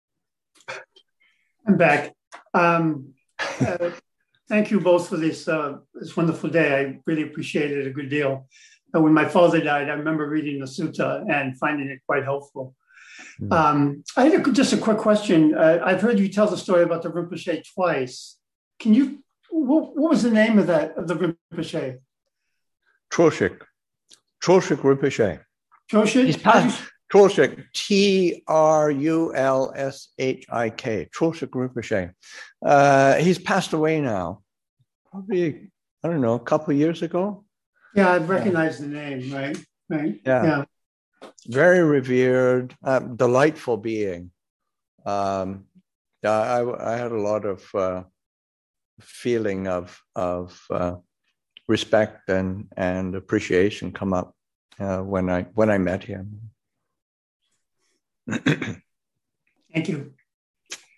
Online from Abhayagiri Buddhist Monastery in Redwood Valley, California